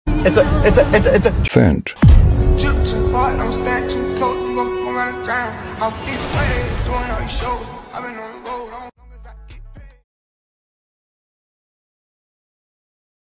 It's fendt 🔥💨 Open pipe fendt 614 lsa, New injection pump and old turbo charged 🤩